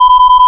MineArm.wav